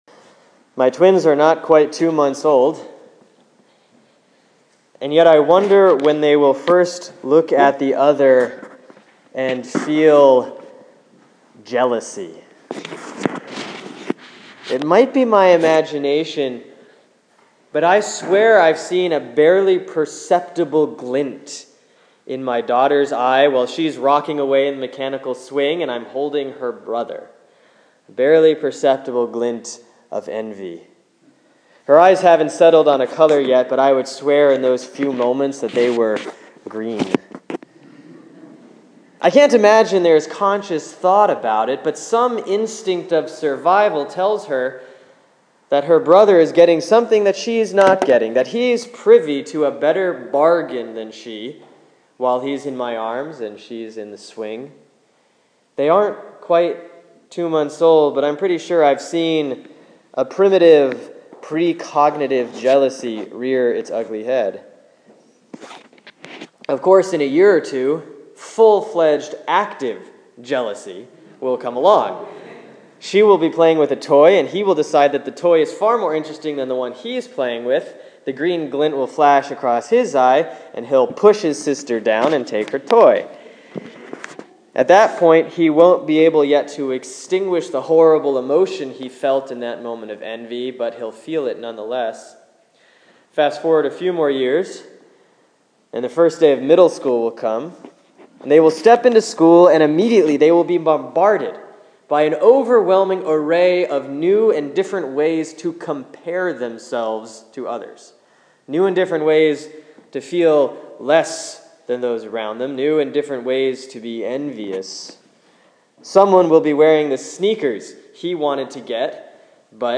Sermon for Sunday, September 21, 2014 || Proper 20A || Matthew 20:1-16